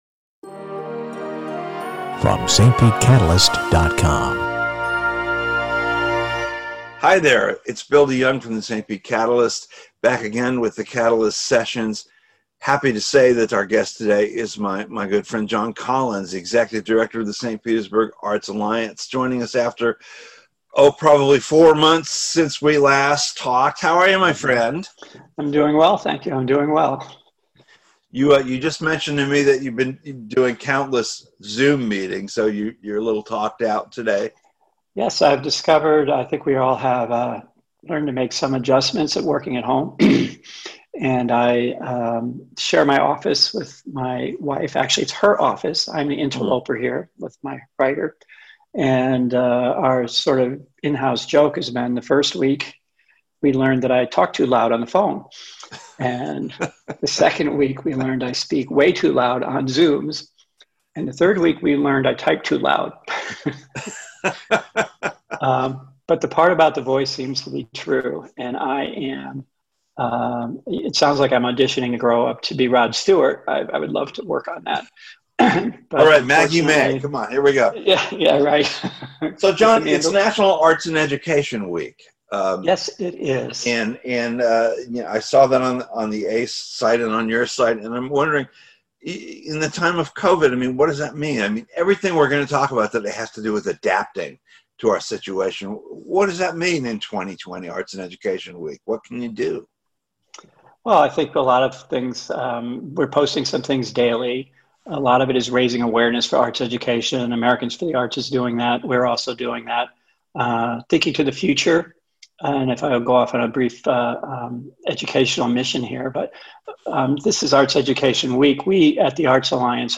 Radio St. Pete Podcast Archive